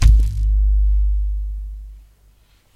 Balloon Bass » Balloon Bass 11
描述：Balloon Bass Zoom H2
标签： sub Bass Balloon
声道立体声